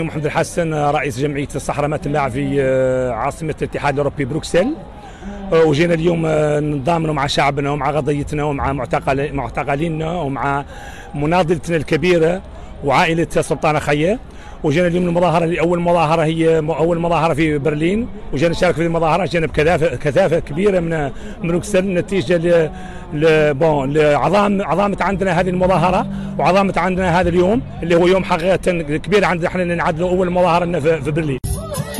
تظاهرة ببرلين للتنديد بذكرى الاجتياح المغربي للصحراء الغربية